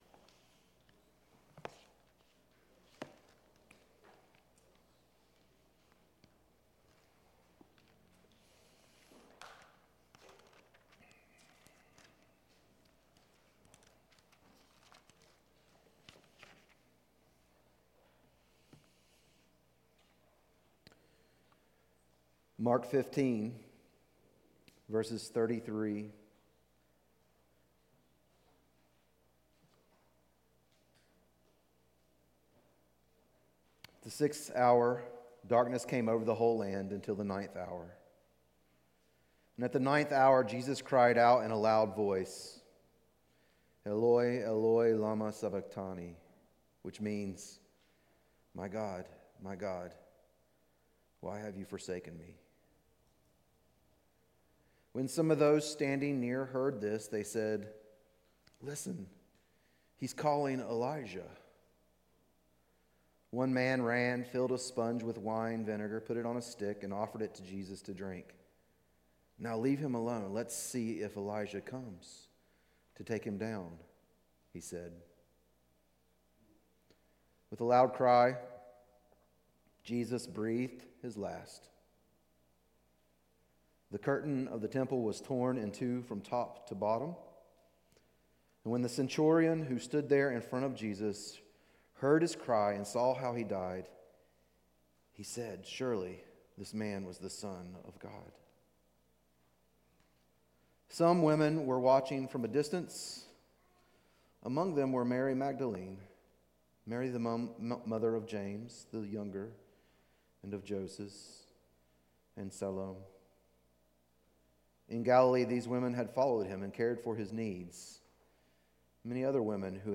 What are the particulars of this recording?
Good Friday Service - The Cross